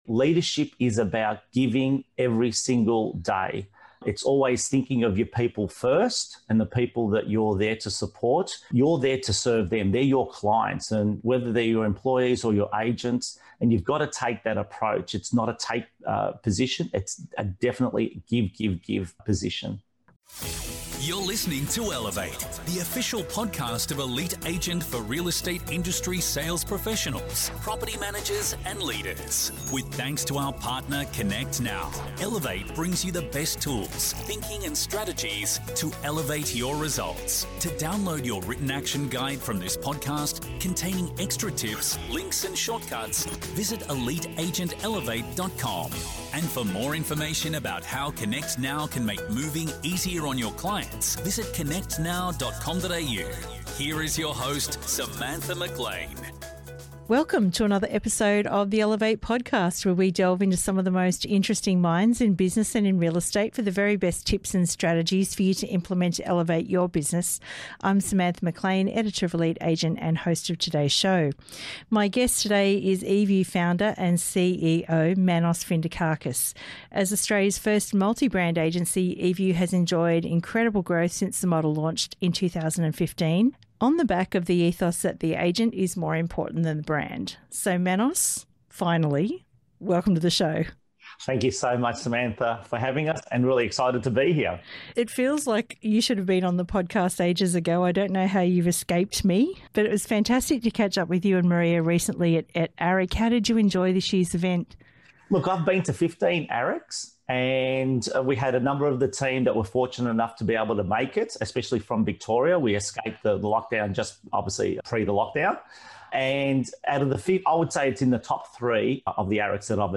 A leadership conversation